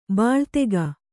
♪ bāḷtega